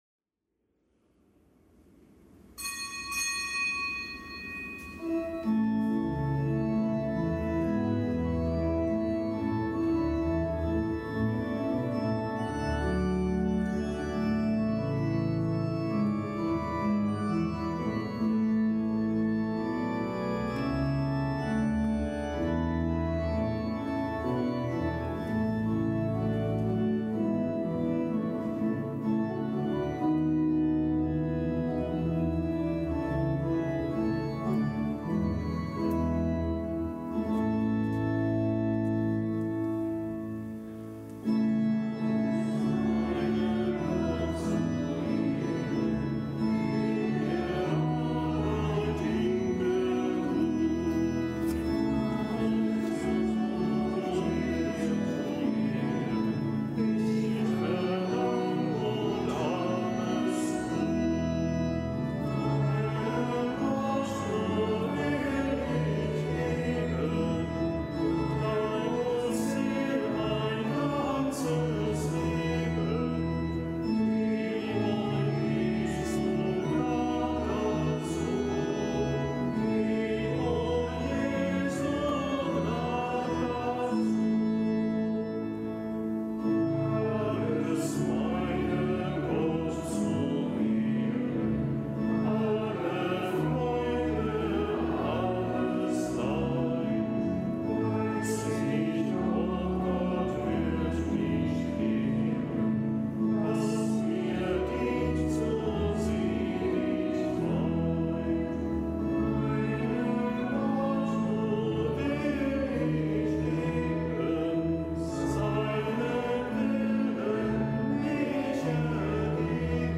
Kapitelsmesse am Freitag der 24. Woche im Jahreskreis